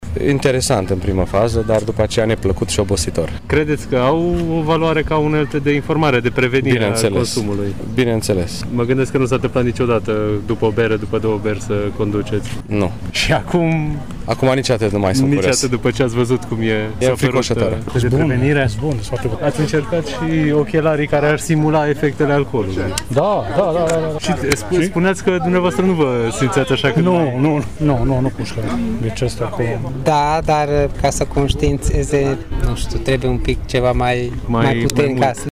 Părerile mureșenilor în privința experimentului de azi au fost împărțite: